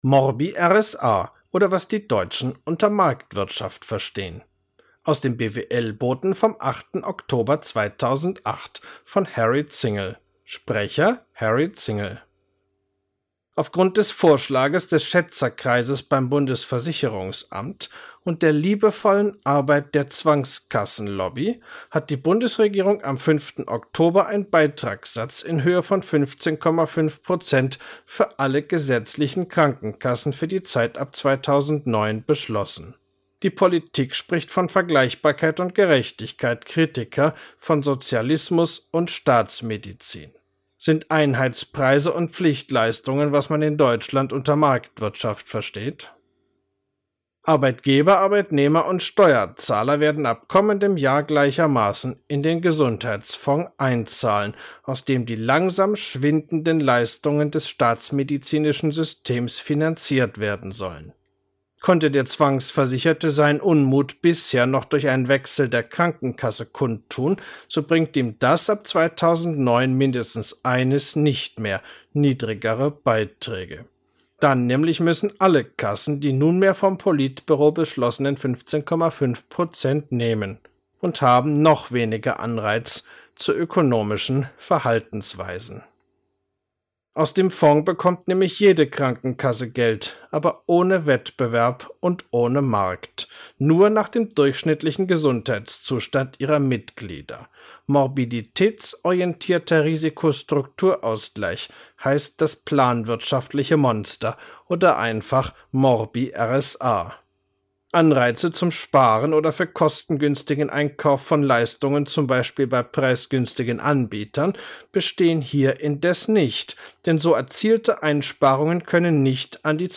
Artikel als MP3 anhören, gesprochen vom Autor: